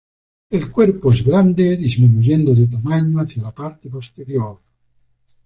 pos‧te‧rior
/posteˈɾjoɾ/